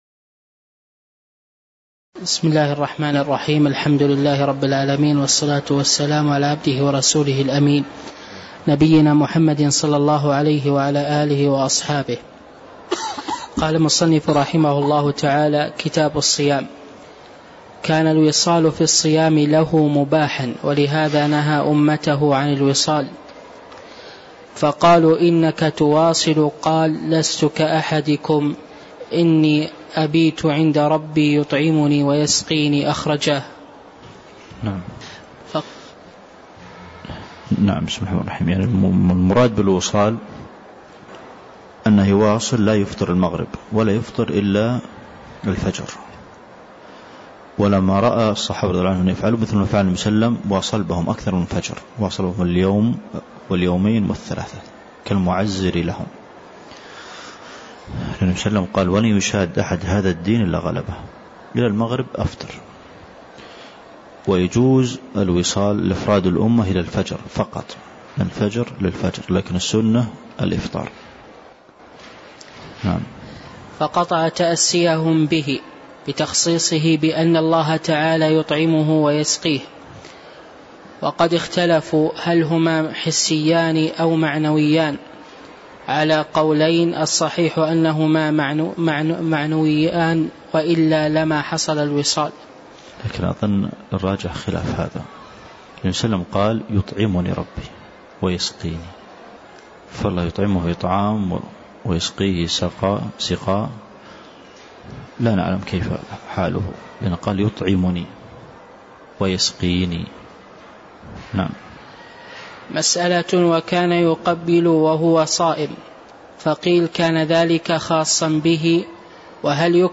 تاريخ النشر ١٣ ذو القعدة ١٤٣٥ هـ المكان: المسجد النبوي الشيخ: فضيلة الشيخ د. عبدالمحسن بن محمد القاسم فضيلة الشيخ د. عبدالمحسن بن محمد القاسم كتاب الصيام (109)